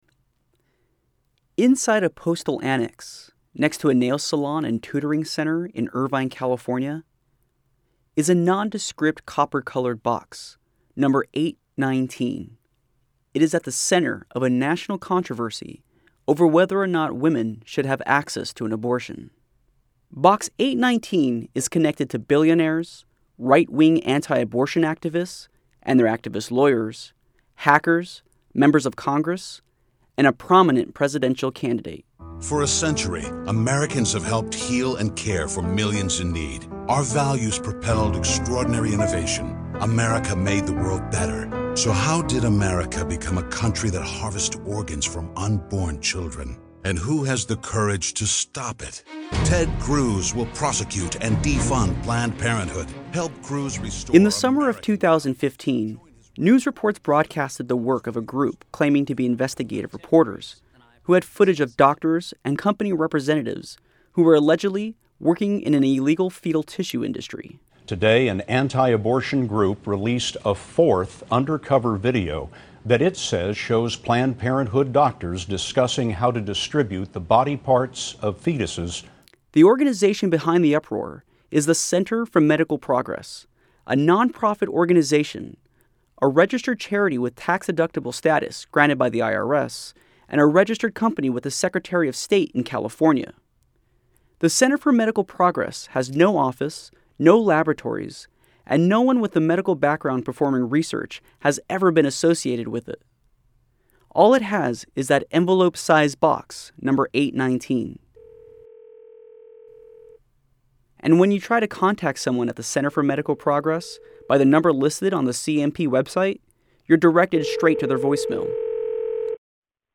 Radio Piece